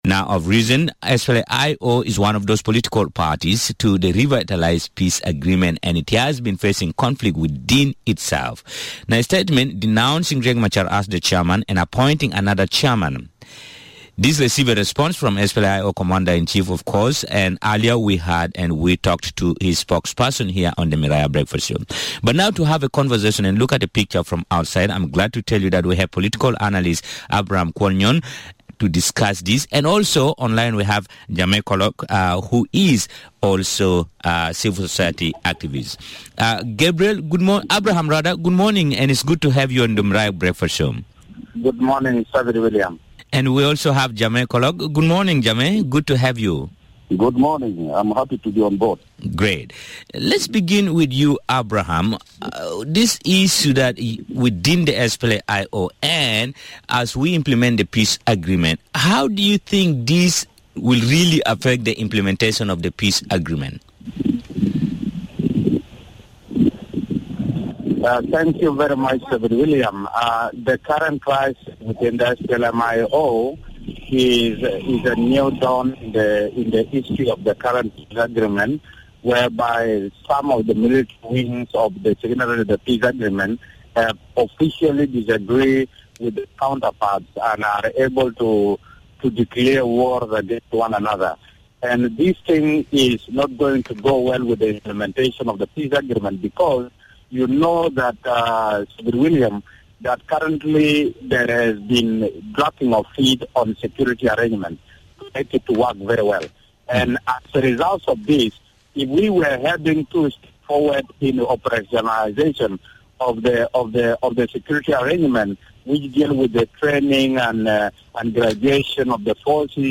Two political analysts